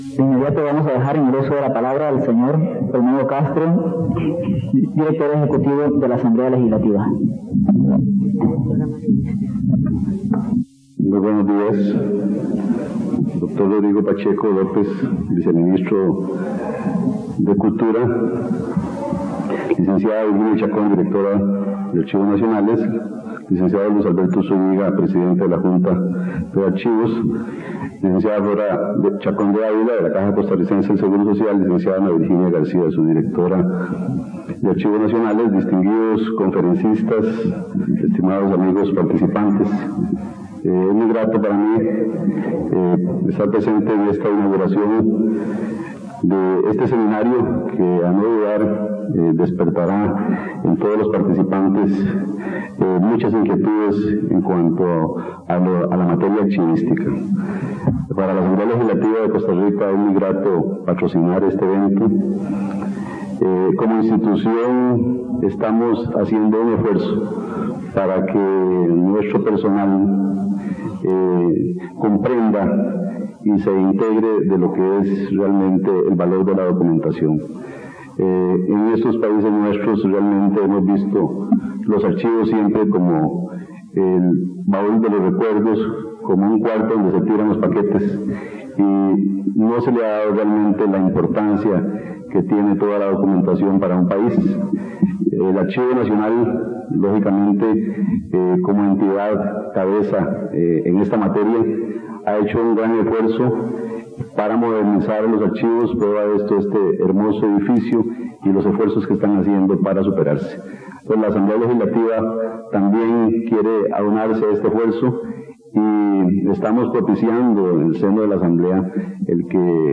Discursos
en el Seminario Internacional sobre las instalaciones y edificios de archivos para la conservación de sus documentos
Notas: Casete de audio y digital